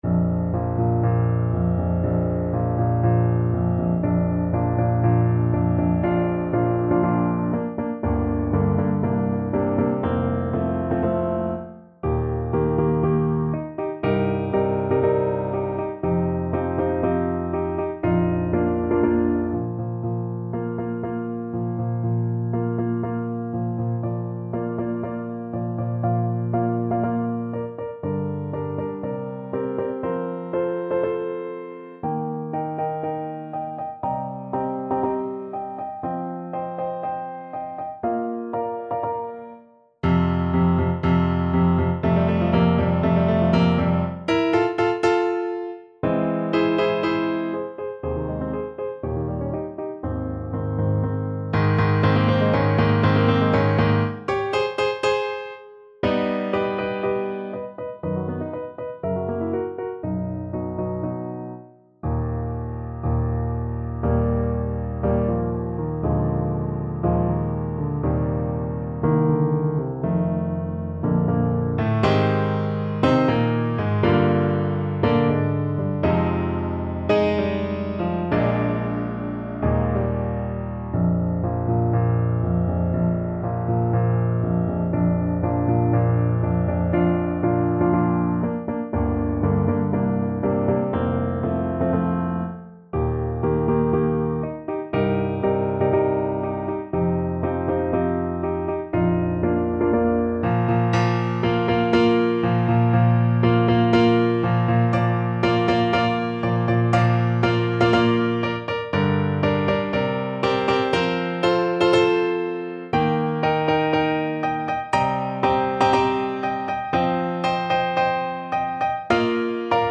~ = 120 Tempo di Marcia un poco vivace
Bb major (Sounding Pitch) G major (Alto Saxophone in Eb) (View more Bb major Music for Saxophone )
Classical (View more Classical Saxophone Music)
grieg_op-65_troldhaug_TPT_kar1.mp3